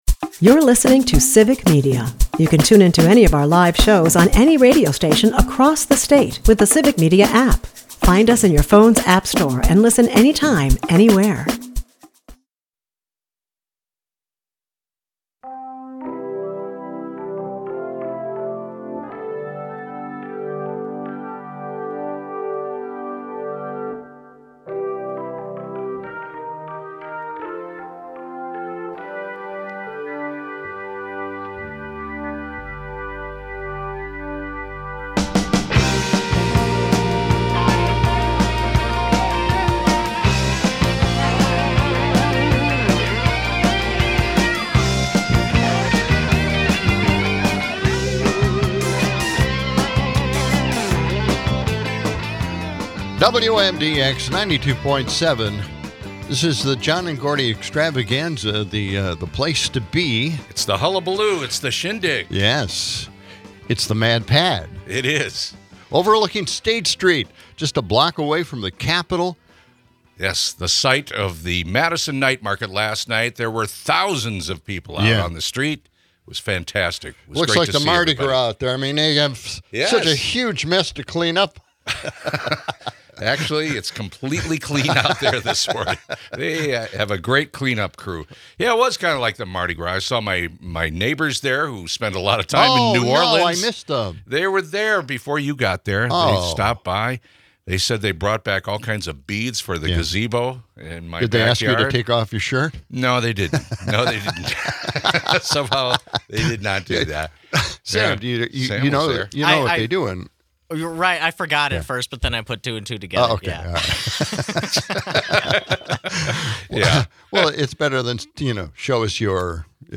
The guys also have a fiery debate on trade deals and homeland security hiring practices, keeping the energy high. Expect laughs, local insights, and listener engagement throughout.